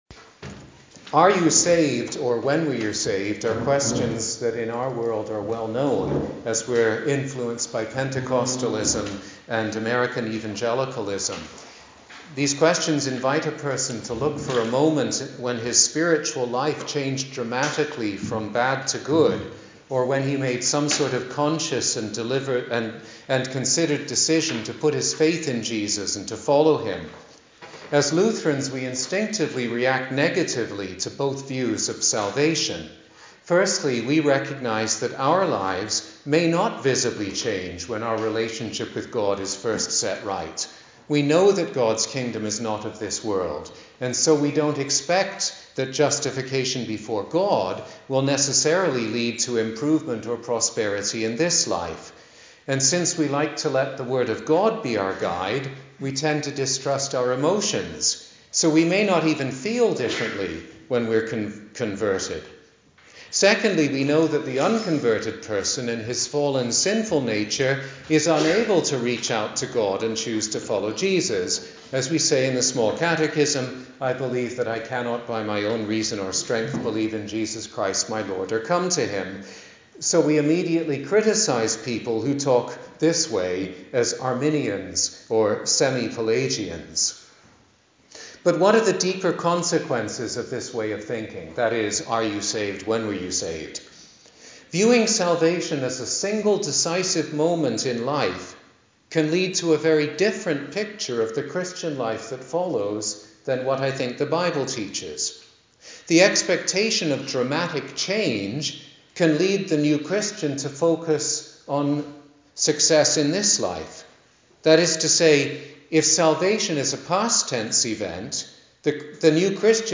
The present recording (in English) comes from a repeat of the lecture at the LCC East District Pastors' Conference,…